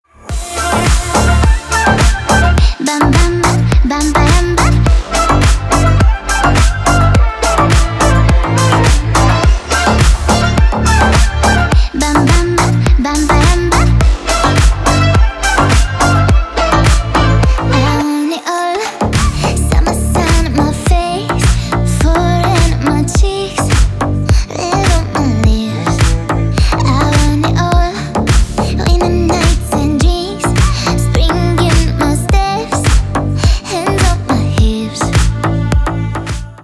Фрагменты танцевальных треков
Танцевальный трек на звонок